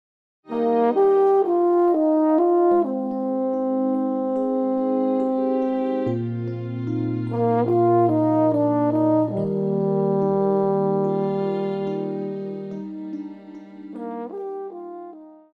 Pop,Christian
French Horn
Band
Instrumental
Ballad
Only backing